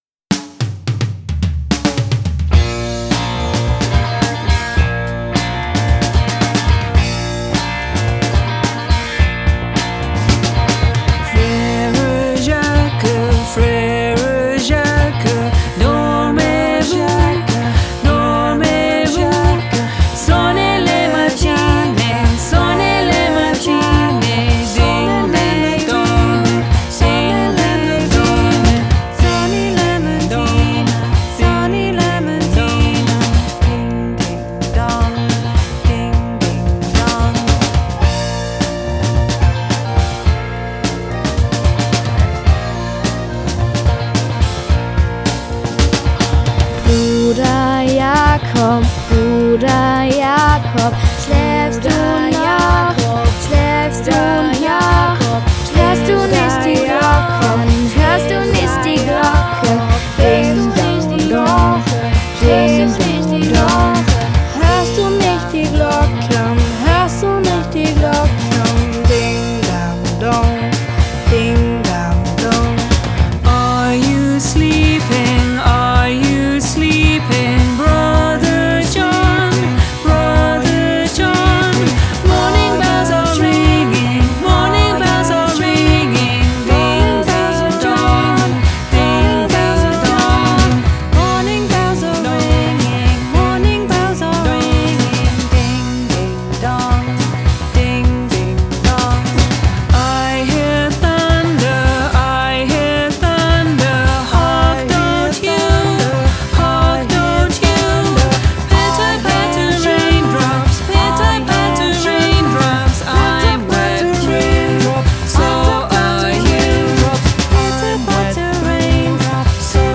Lernlieder
In unserer Version werden alle 3 Sprachen zusammengeführt.